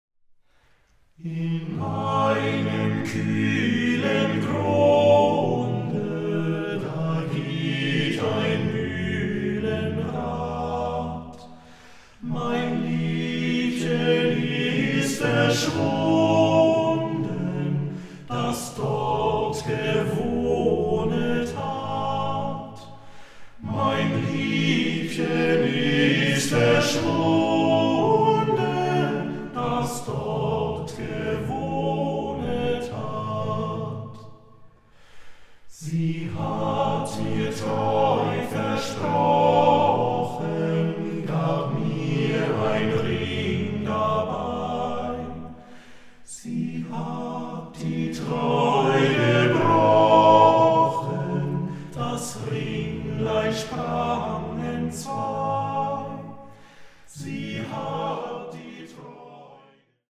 award-winning vocal ensemble
the eight singers revive an entire folk culture!